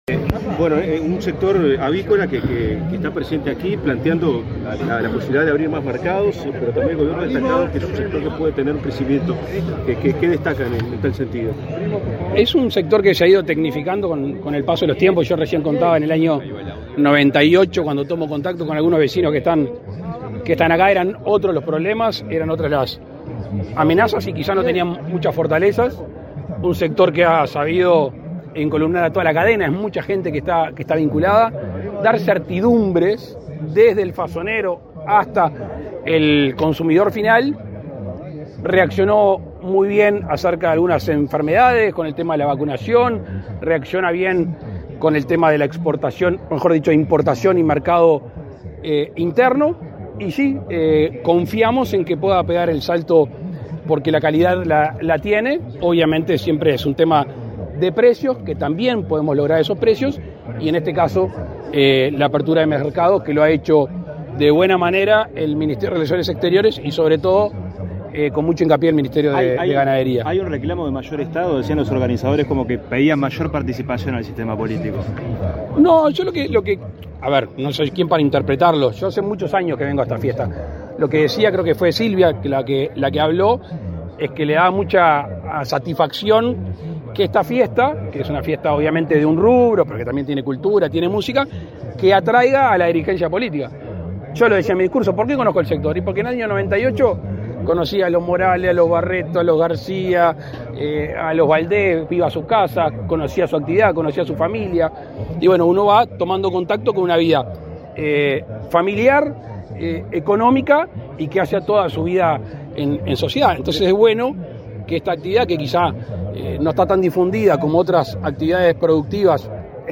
Declaraciones a la prensa del presidente de la República, Luis Lacalle Pou
Declaraciones a la prensa del presidente de la República, Luis Lacalle Pou 01/02/2024 Compartir Facebook X Copiar enlace WhatsApp LinkedIn Tras participar en la 12.ª Edición de la Fiesta del Pollo y la Gallina, en Canelones, este 1.° de febrero, el presidente de la República, Luis Lacalle Pou, realizó declaraciones a la prensa.